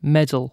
Ääntäminen
IPA : /ˈmɛdəl/